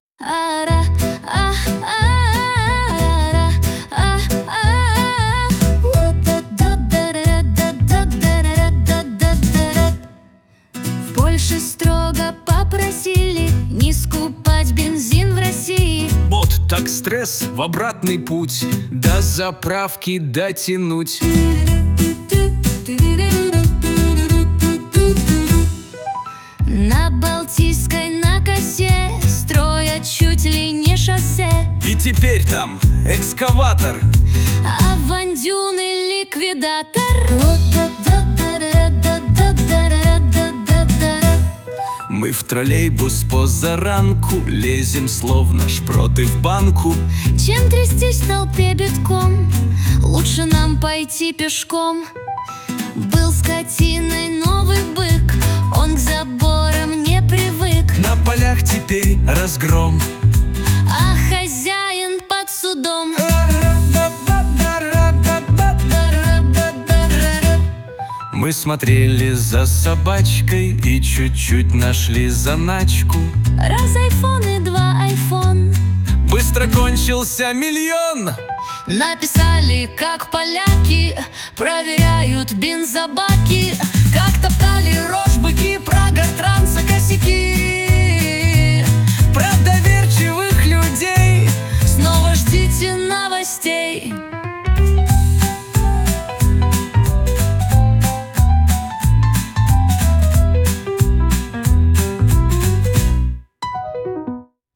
Комические куплеты о главных и важных событиях